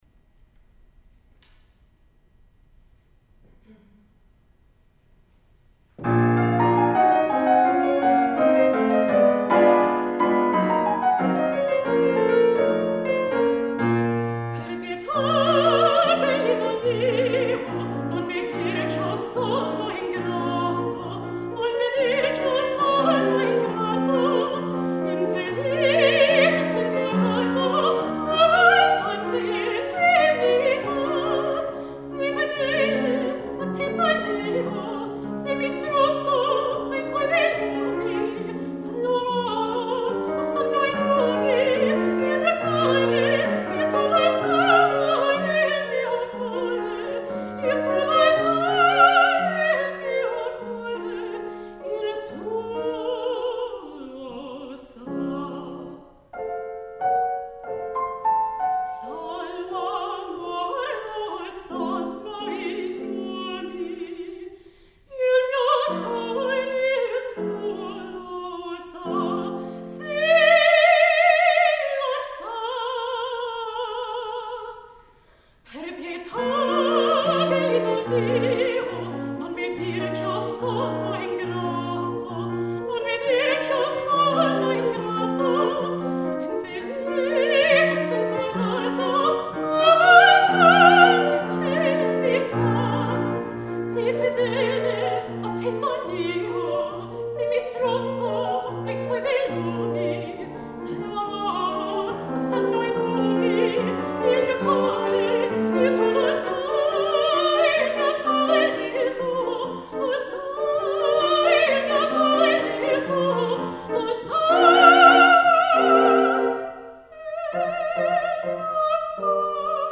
Mezzo-Soprano
Senior Recital